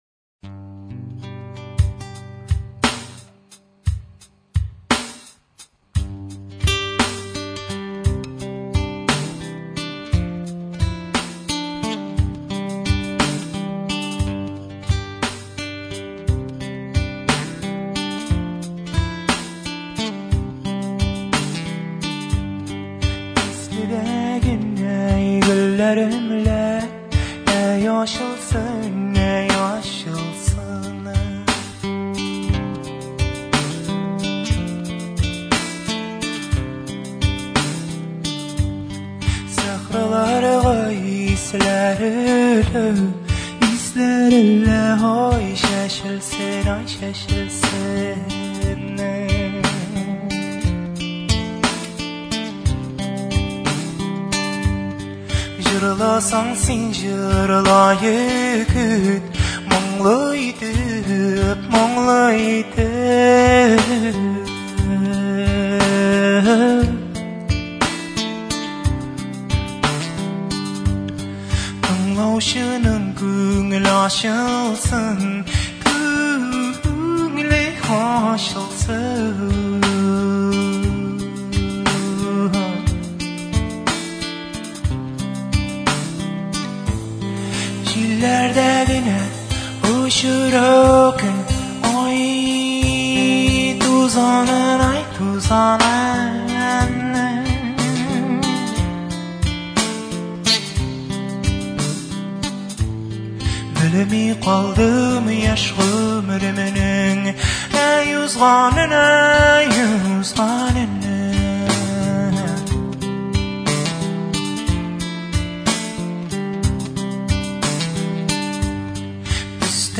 Второй Каэтано Велосо поет на татарском